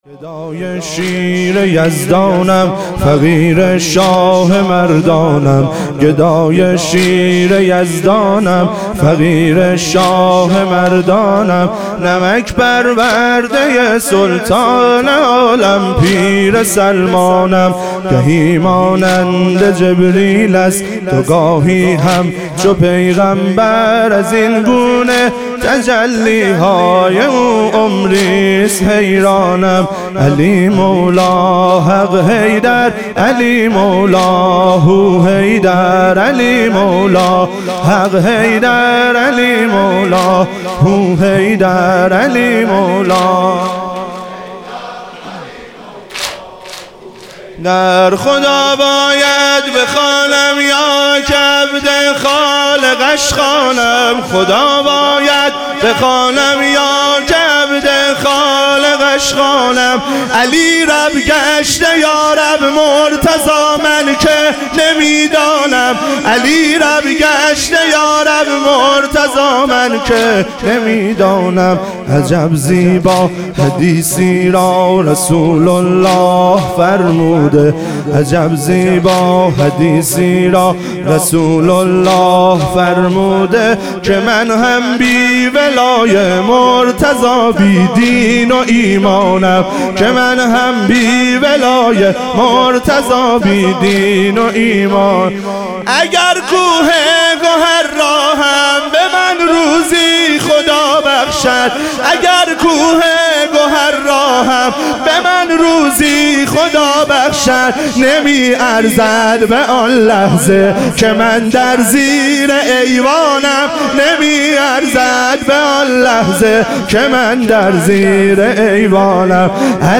ظهور وجود مقدس حضرت زینب علیها سلام - واحد